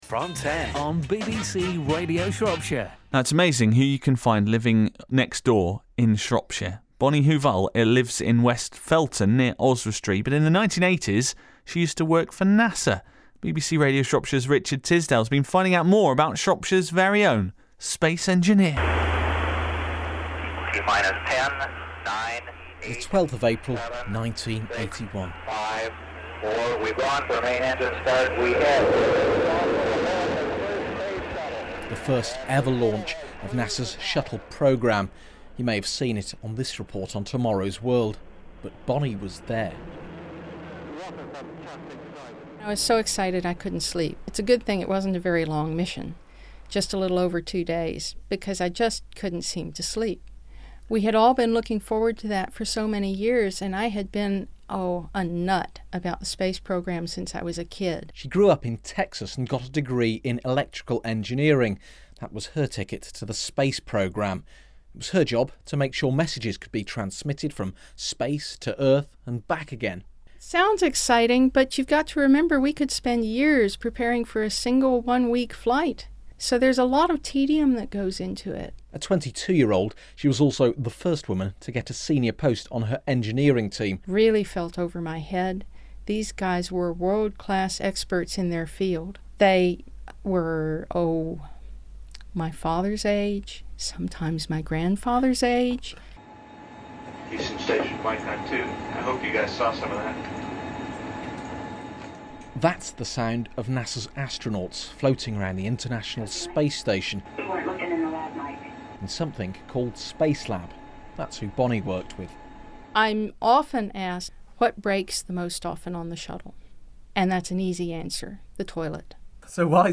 The day before the meeting, during prime drive-time slots the station aired four clips from the interview ranging up to about 4 minutes long.